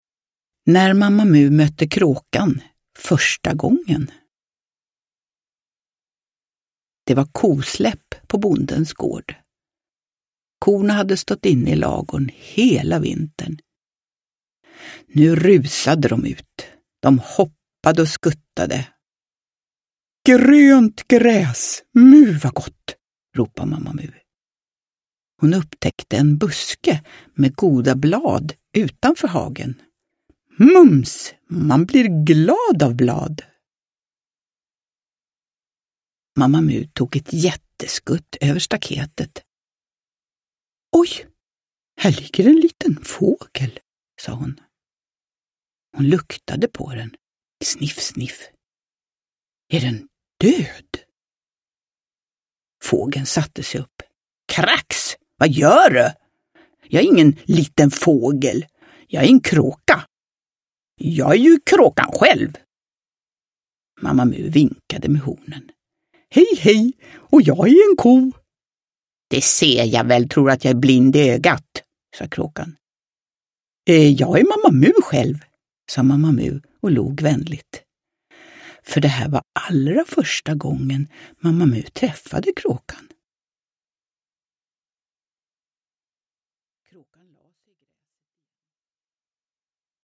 När Mamma Mu mötte Kråkan första gången – Ljudbok – Laddas ner
Uppläsare: Jujja Wieslander